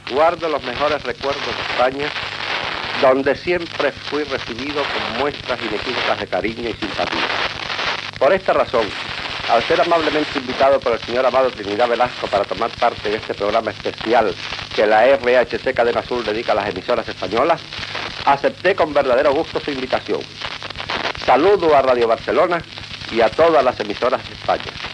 Salutació del músic cubà Ernesto Lecuona,des de l'emissora RHC Cadena Azul de l'Havana.
Fragment extret d'"Historia de Radio Barcelona 1924-1974" (1974).